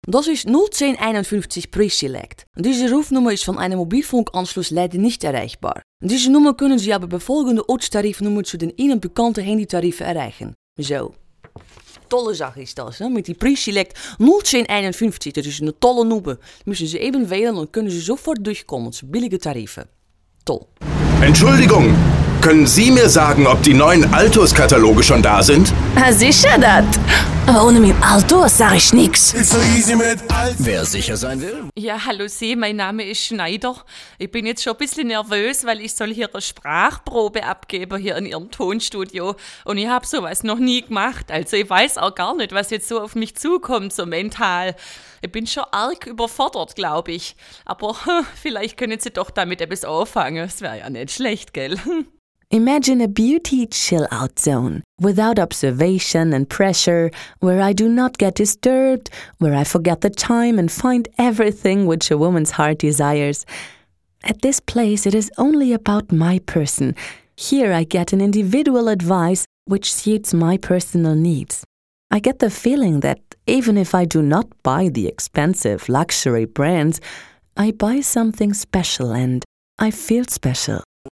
deutsche Profi Sprecherin mit einer vielseitigen Stimme: freundlich warm - szenemässig cool - sinnlich lasziv . Spezialität: Dialekte und Akzente
Sprechprobe: Sonstiges (Muttersprache):